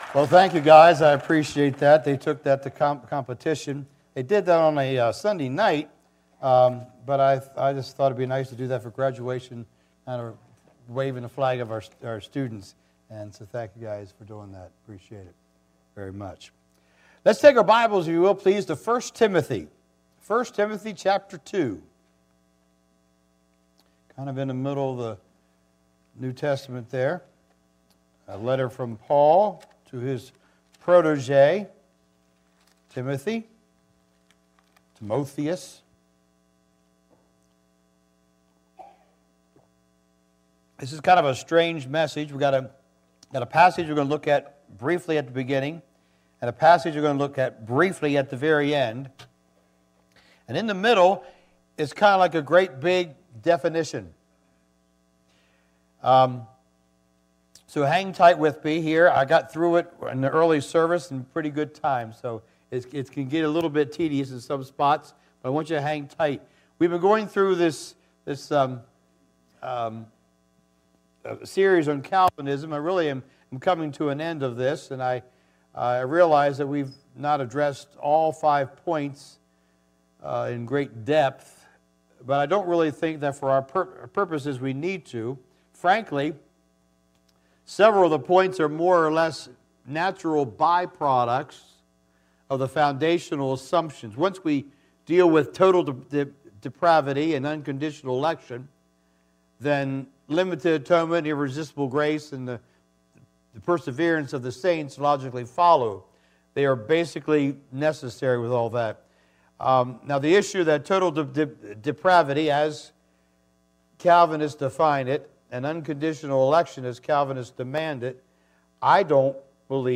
Passage: I Timothy 2:1 Service Type: Sunday Morning